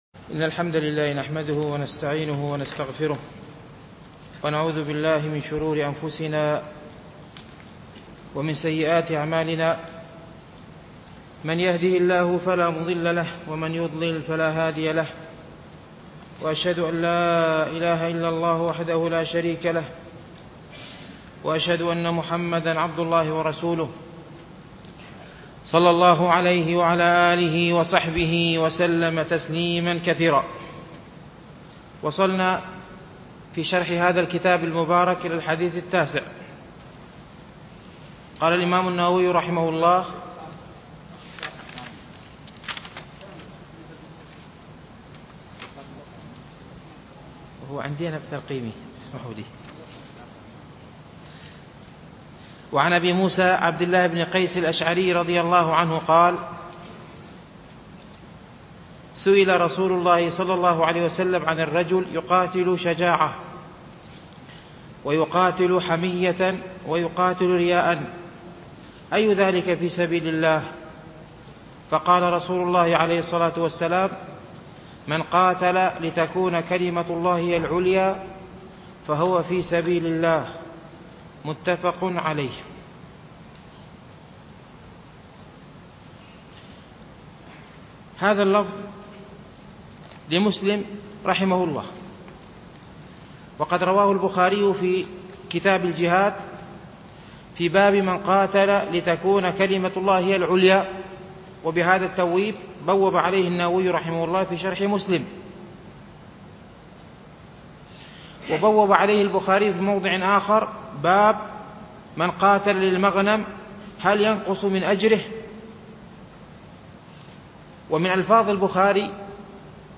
شرح رياض الصالحين - الدرس الخامس
MP3 Mono 11kHz 32Kbps (CBR)